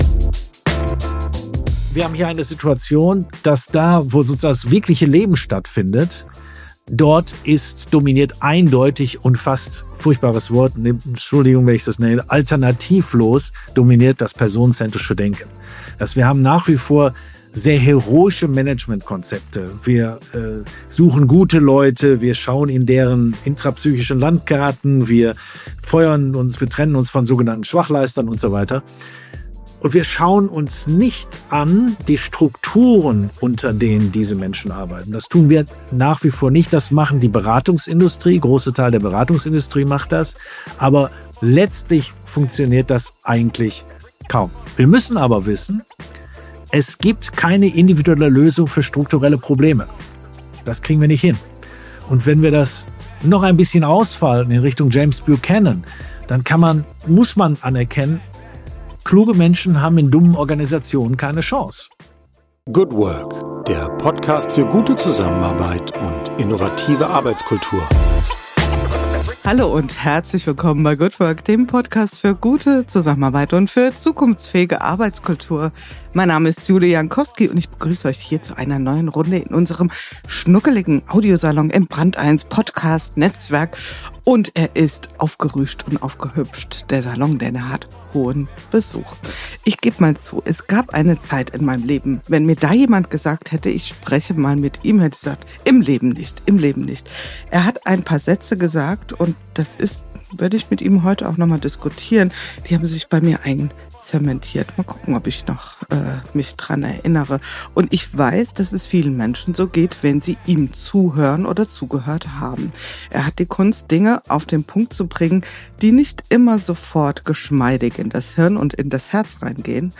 Ein Gespräch über Leadership, Zukunftsfähigkeit und die unbequeme Kunst, wirklich zu führen.